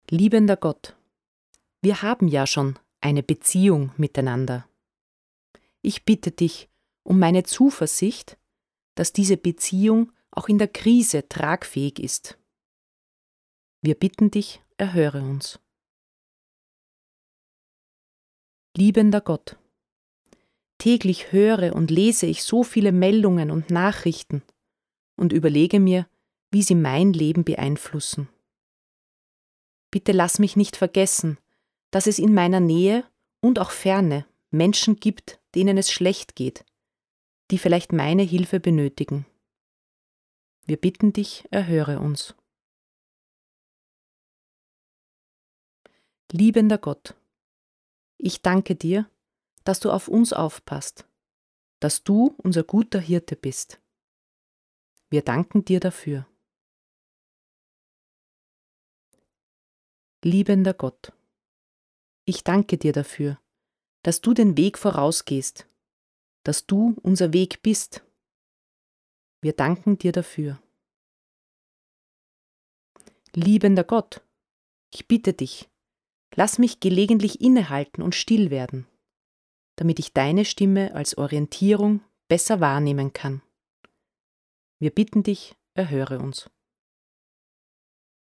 Fürbitten
07_Fürbitten.mp3